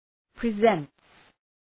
Προφορά
{‘prezənts}